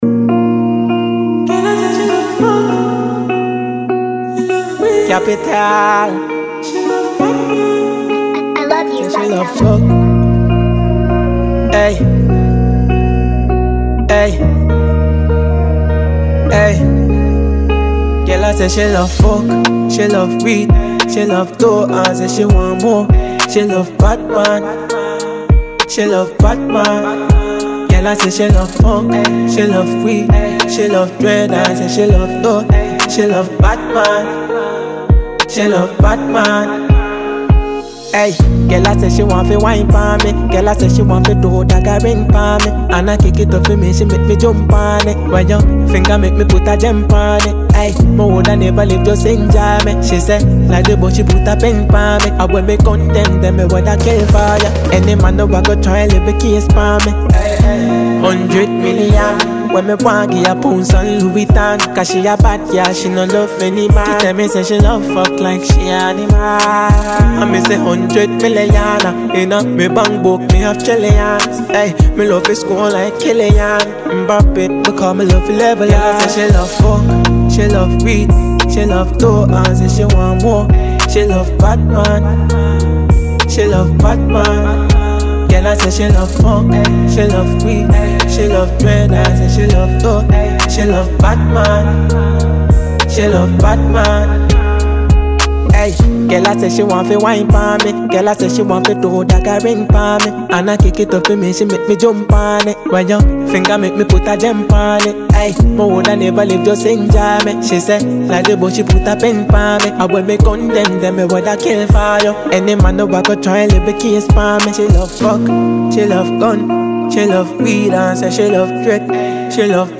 a Ghanaian dancehall act